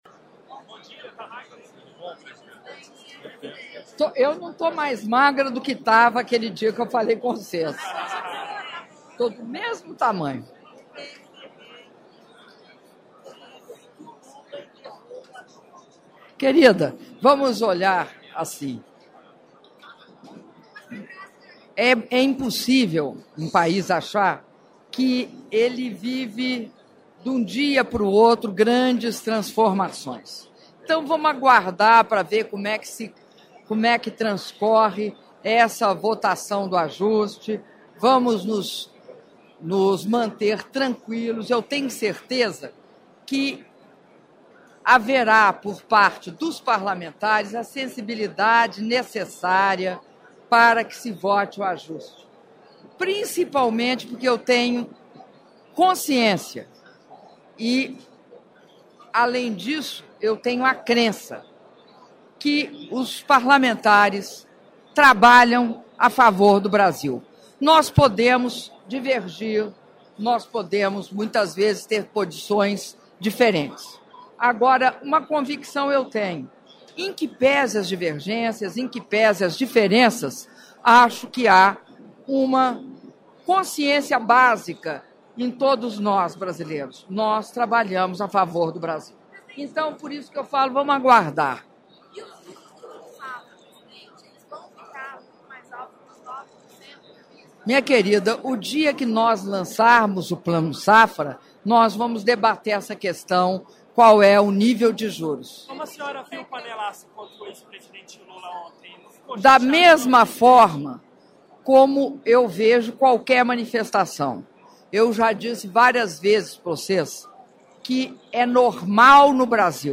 Áudio da entrevista coletiva concedida pela presidenta da República, Dilma Rousseff, após cerimônia de Lançamento do Plano Nacional de Defesa Agropecuária - Brasília/DF (3min49s)